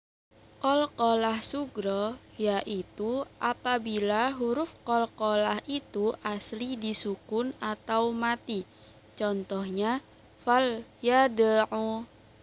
qalqalahsugra.wav